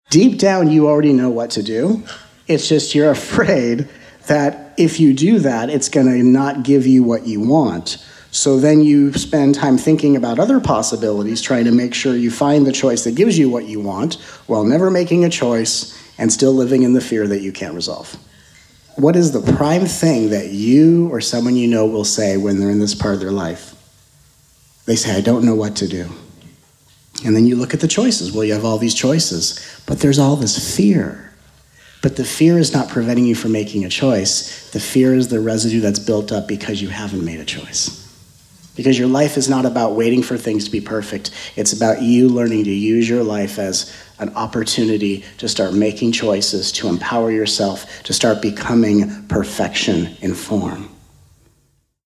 Recorded at their January 2017 Encinitas weekend immersion, this download offers brand-new teachings and potent transmissions of healing energy to assist you in opening the doorway of freedom and exploring life beyond the veil of fear.
Due to the powerful energies in the room during our events, it is common to experience fluctuations in sound quality throughout the recording.